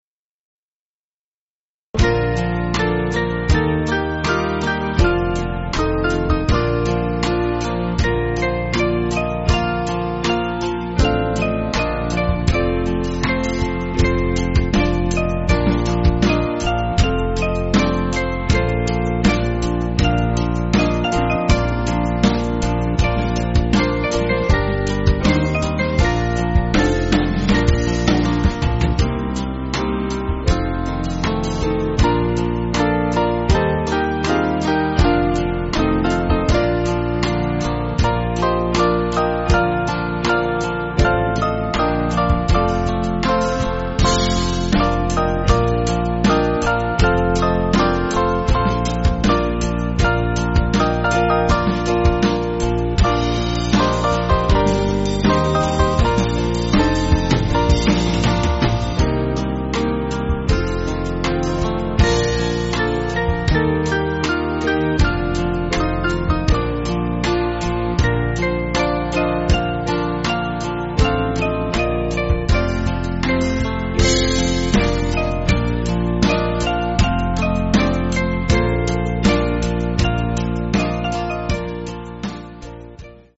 Small Band
(CM)   4/Bb